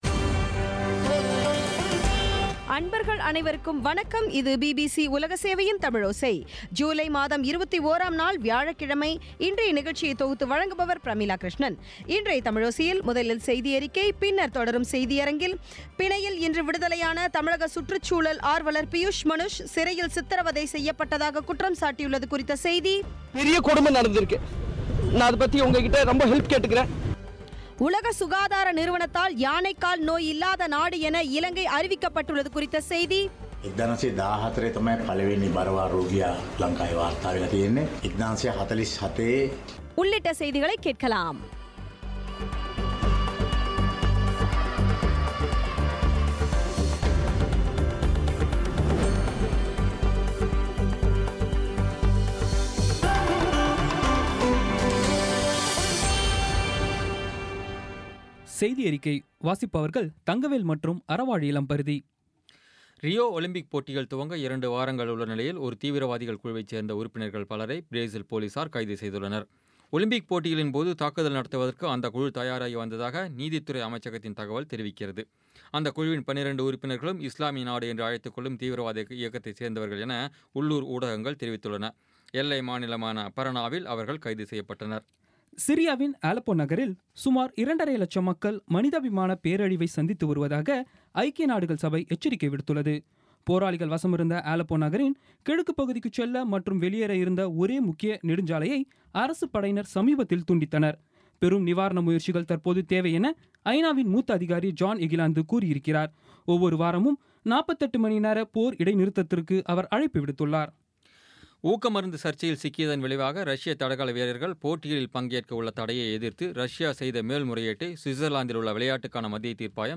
இன்றைய தமிழோசையில், முதலில் செய்தியறிக்கை பின்னர் தொடரும் செய்தியரங்கில், பிணையில் இன்று விடுதலையான தமிழக சுற்றுச் சுழல் ஆர்வலர் பியூஸ் மனுஷ் சிறையில் சித்தரவதை செய்யப்பட்டதாக குற்றம்சாட்டியுள்ளது குறித்த செய்தி உலக சுகாதார நிறுவனத்தினால் யானைக் கால் இல்லாத நாடு என இலங்கை அறிவிக்கப்பட்டுள்ளது குறித்த செய்தி ஆகியவை கேட்கலாம்